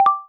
notification.wav